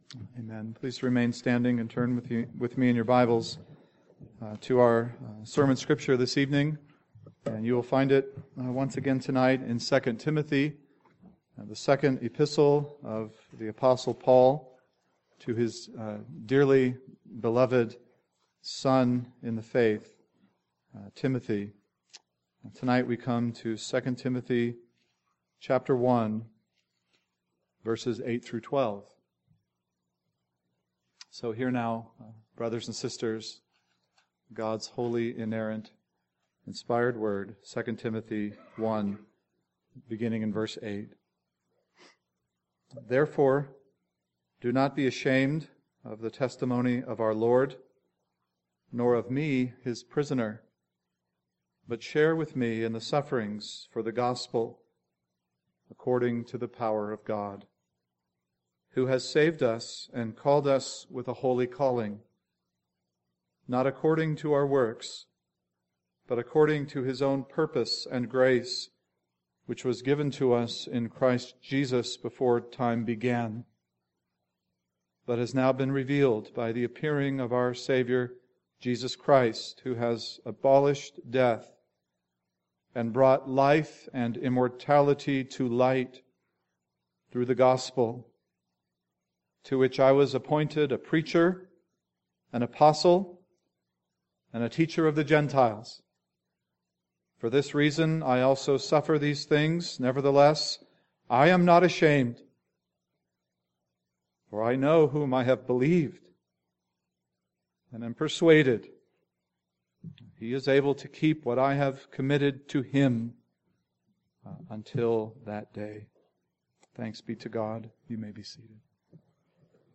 PM Sermon